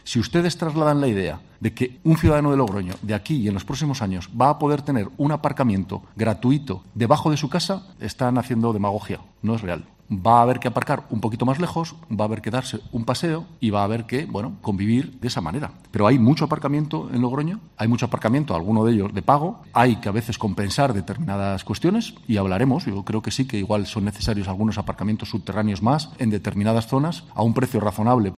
En el Pleno ordinario de marzo, celebrado hoy en el Ayuntamiento de Logroño, el alcalde, Pablo Hermoso de Mendoza, ha subrayado que "hay que decirle a la gente, a la cara, que no van poder aparcar gratis debajo de casa" y, aunque según sus datos, en el conjunto de la ciudad "hay plazas de estacionamiento suficientes", sí que en algunas zonas puede ser necesario construir algunos aparcamientos subterráneos.